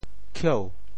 巧 部首拼音 部首 工 总笔划 5 部外笔划 2 普通话 qiǎo 潮州发音 潮州 kieu2 白 ka2 文 中文解释 巧〈形〉 (形声。